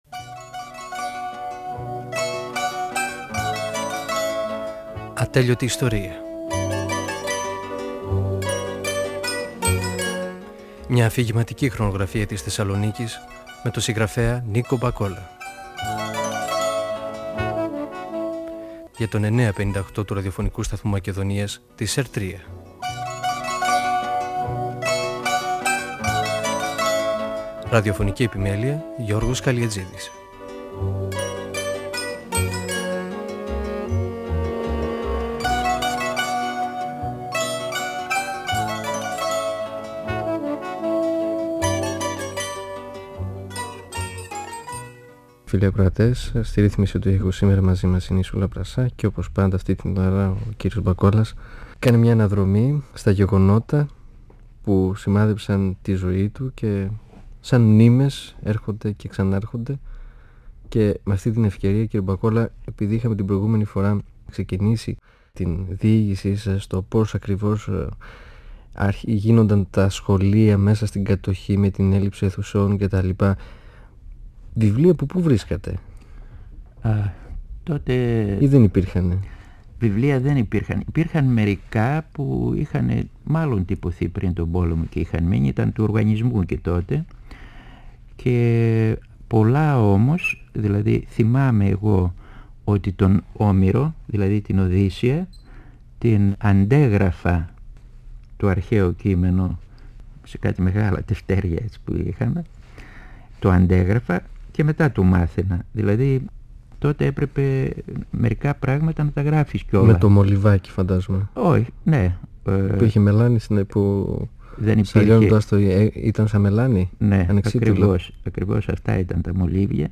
Ο πεζογράφος Νίκος Μπακόλας (1927-1999) μιλά για τα παιδικά του χρόνια στη γειτονιά του, την περιοχή της οδού 25ης Μαρτίου.
Η συνομιλία-συνέντευξη του Ν.Μ.